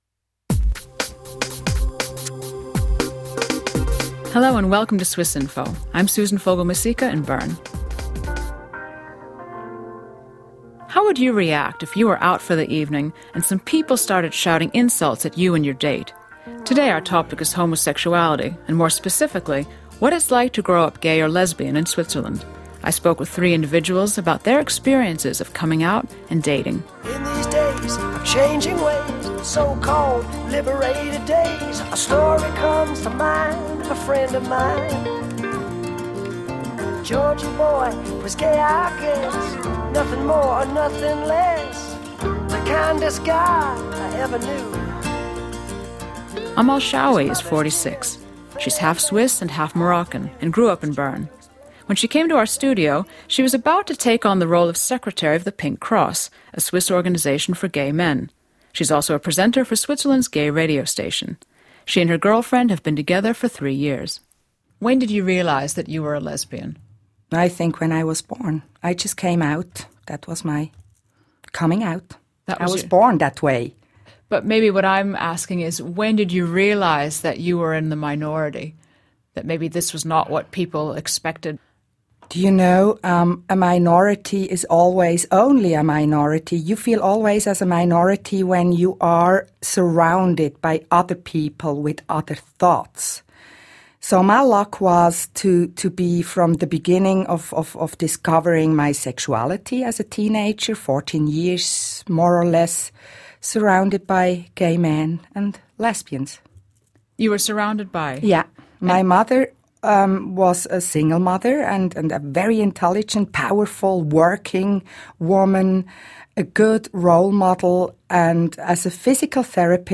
What’s it like to be gay or lesbian in Switzerland? Three Bern-based homosexuals talk about their experiences with school, coming out and dating.
people talking about youth experience